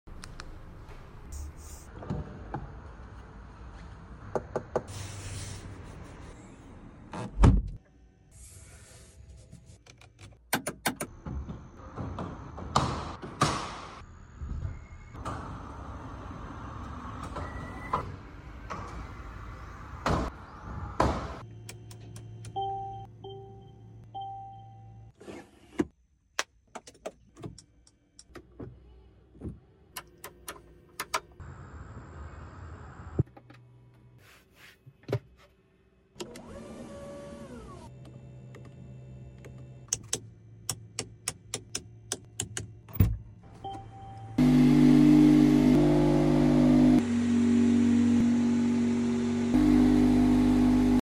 sounds of an AMG ONE sound effects free download